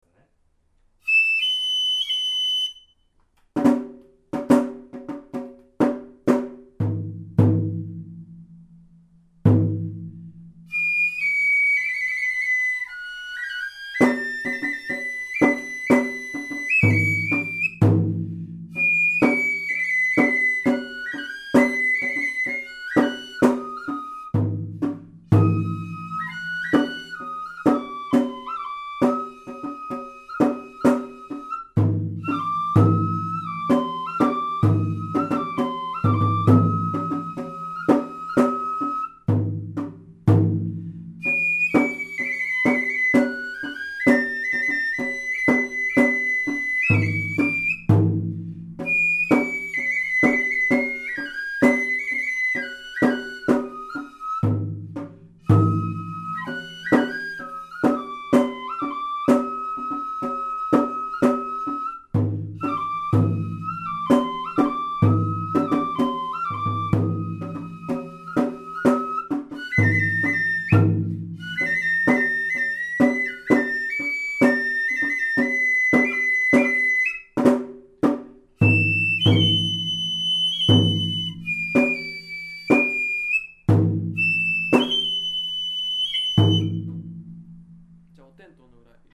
なお、笛の方はとっても上手ですが、太鼓（実は大部分が私）は数箇所間違っております。
ただ、音源に合せて吹きやすいように、今回は高音の部分から全部吹いてもらいました。